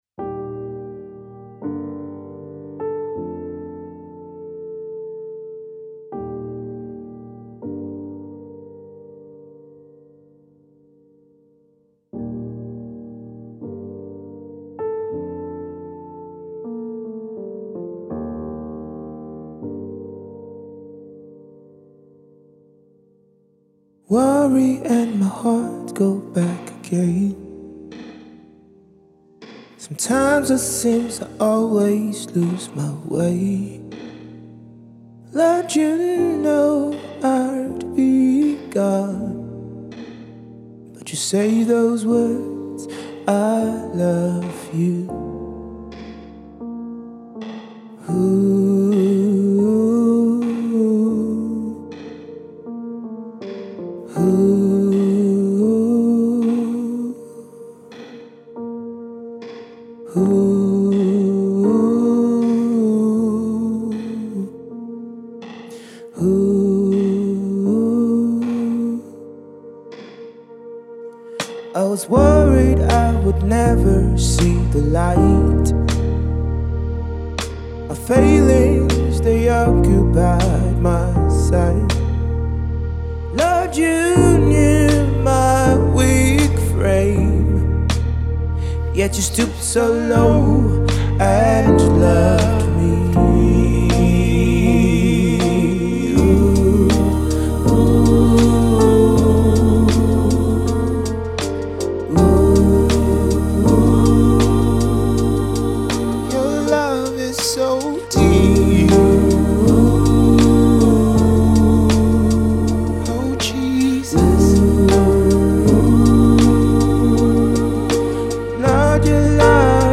powerful and uplifting track